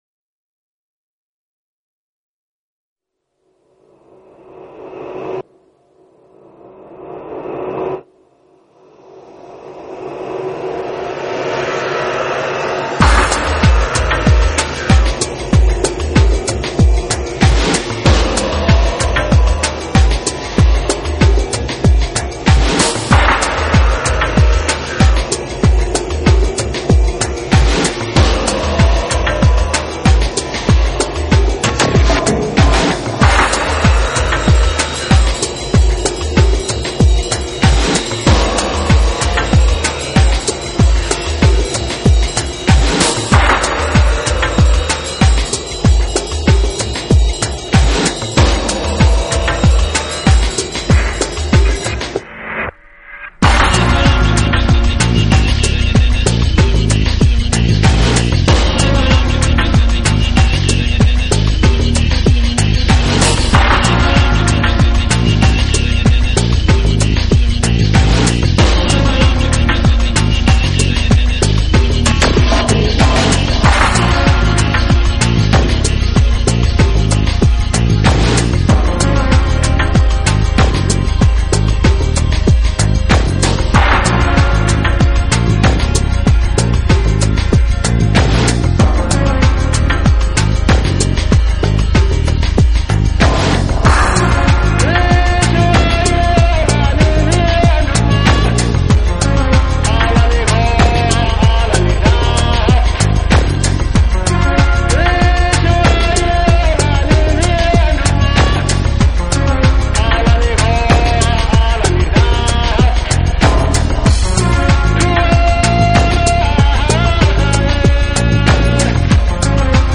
专辑歌手：纯音乐
一张令你听后感到清新，宁静，忘我，释放的迷幻发烧音乐天碟。
融合了中东迷幻与西藏的神秘感，穿插了佛教的宁静，加入了现在
最流行的电子而制作而成的现代最富争议的流行轻音乐。
一流的录音效果，是今世纪不可错过的经典唱片，试音必备。